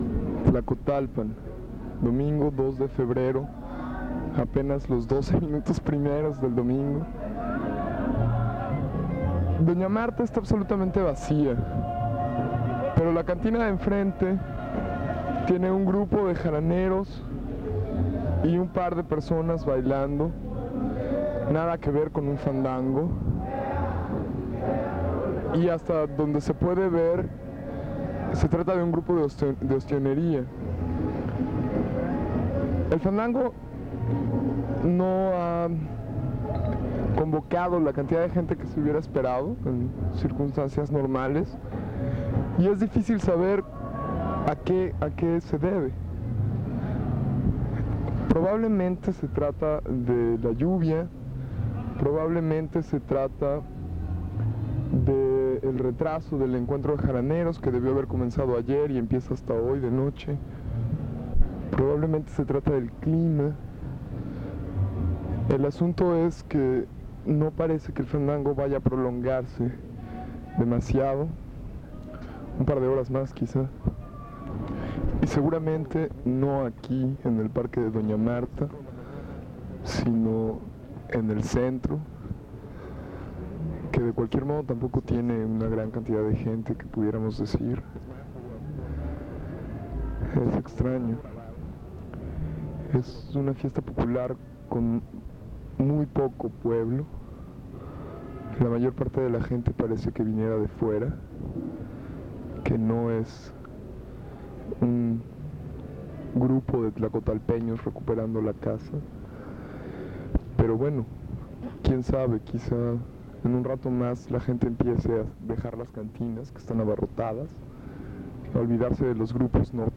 02 Ambiente en la plaza Zaragoza
Fiesta de La Candelaria: investigación previa